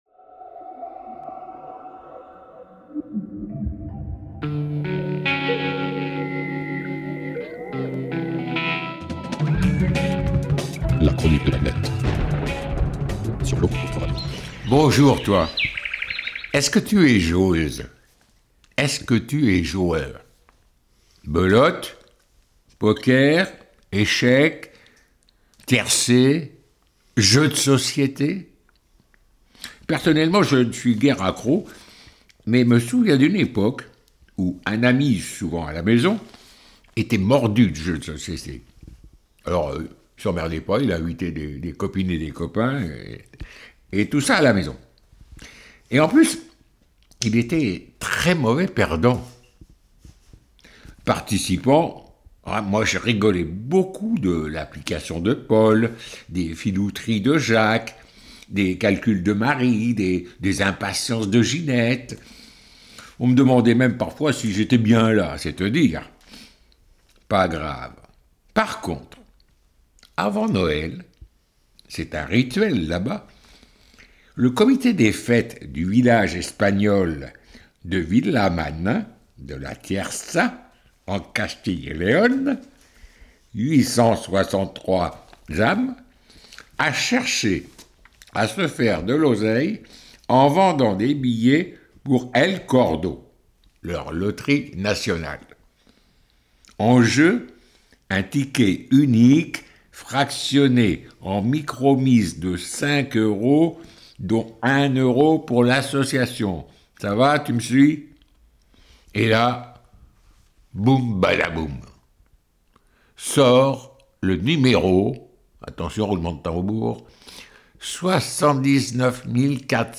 Quelques petites minutes pour un billet d'humeur style " j'aime, je partage " et autres pirouettes contre la bêtise humaine quand elle colle à nos semelles.